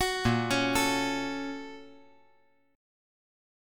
BbmM7#5 Chord
Listen to BbmM7#5 strummed